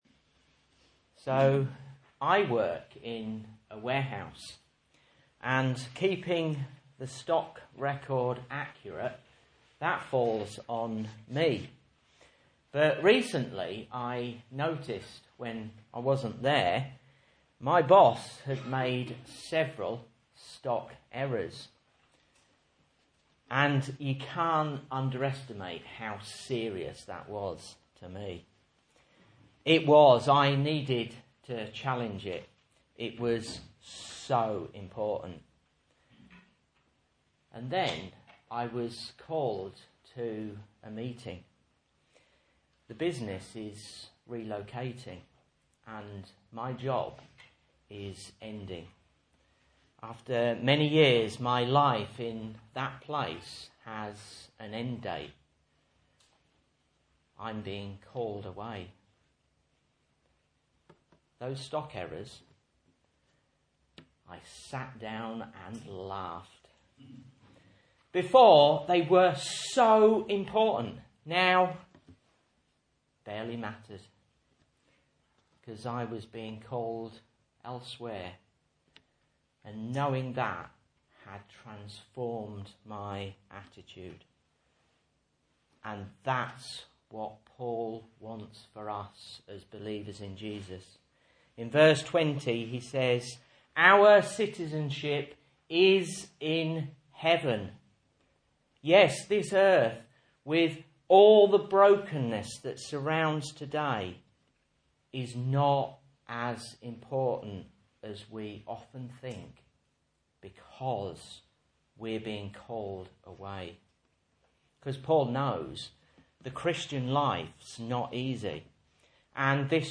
Message Scripture: Philippians 3:17-4:3 | Listen